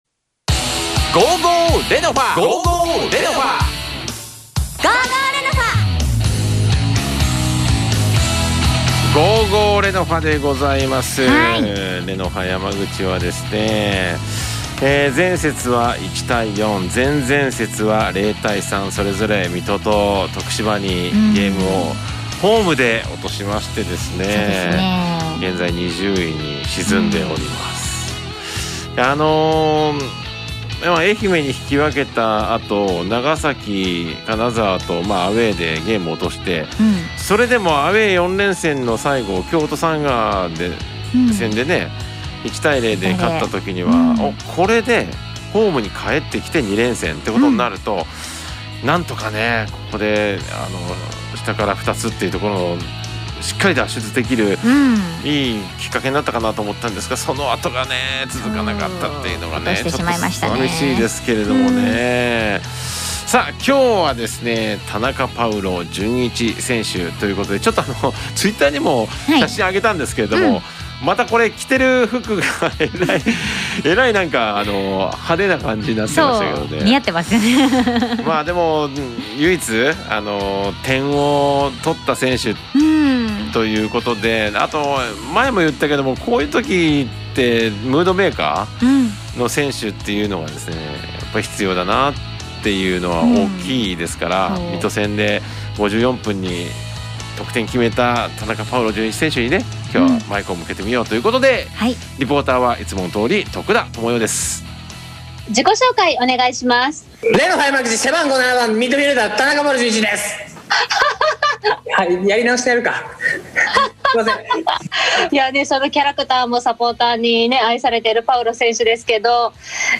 ※インタビューは通信会議アプリを使って収録したものです。